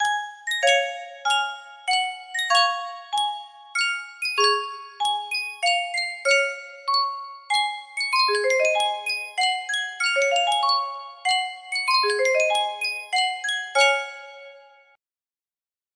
Sankyo Music Box - Rock of Ages BUZ music box melody
Full range 60